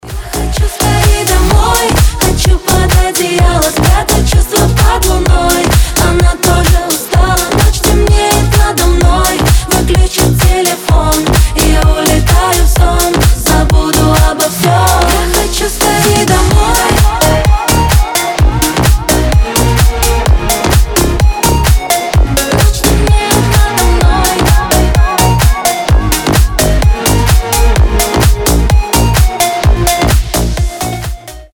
• Качество: 320, Stereo
громкие
Club House
энергичные
ремиксы